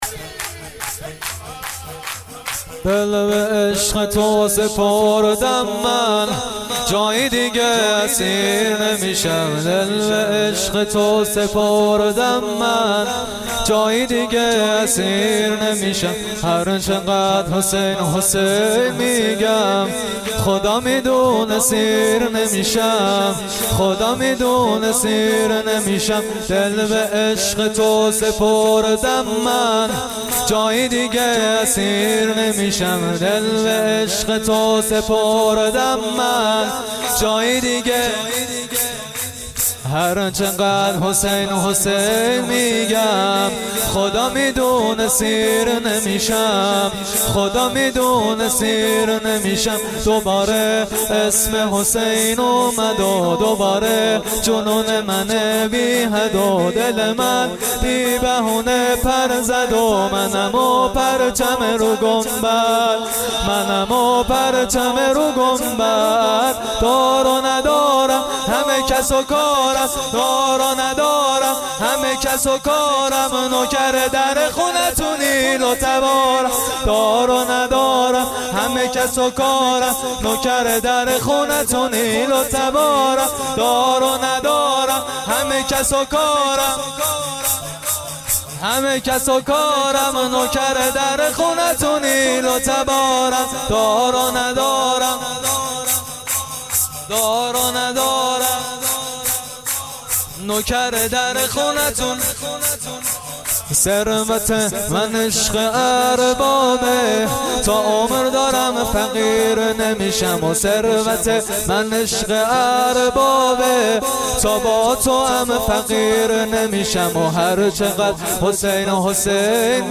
میلاد سرداران کربلا98